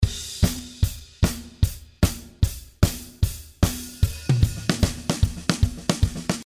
Drum loops in the style of " All I Want For Christmas " by mariah carey. (shuffle groove).
This package contains 33 loops in total with more then 10 drum fills and tom fills.
The loop variations are Bass drum and snare beat with hihat and all so loops with bass drum,snare and ride cymbals.